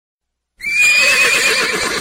Kategorie Zwierzęta